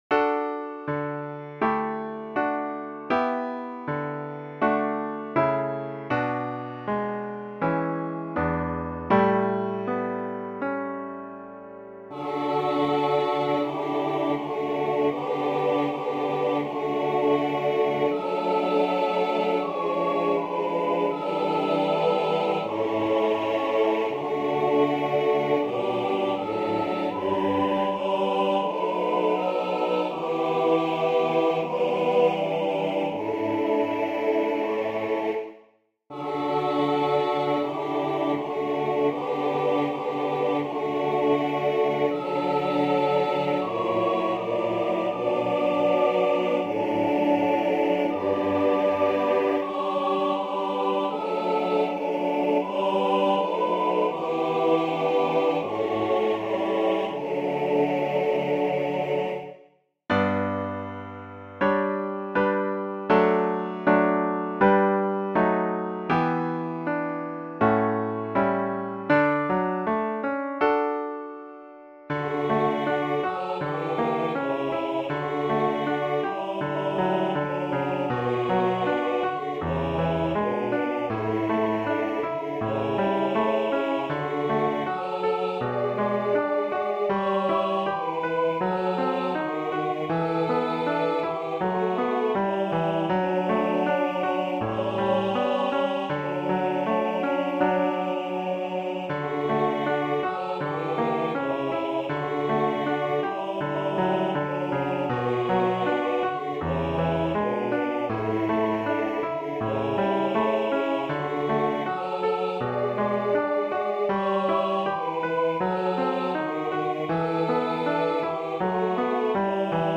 I blended three of my favorite hyms together into one choir piece.
Voicing/Instrumentation: SATB We also have other 36 arrangements of " Our Savior's Love ".